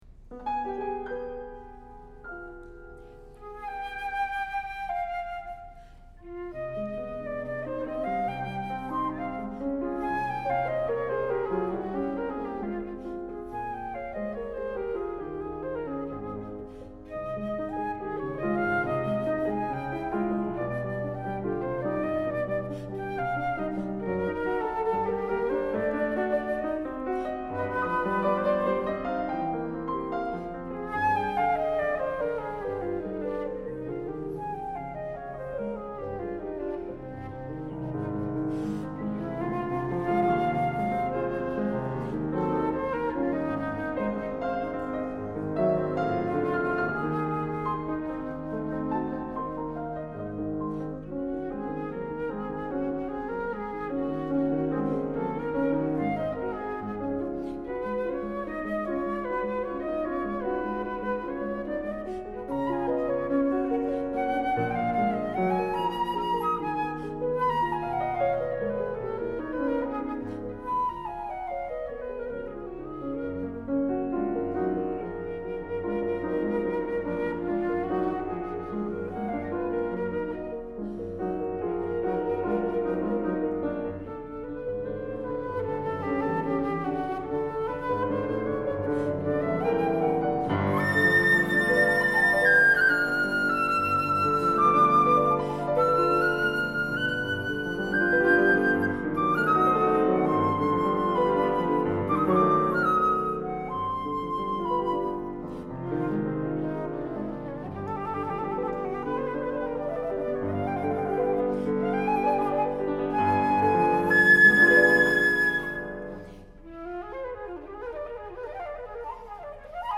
Suite for Flute & Piano, I. Moderato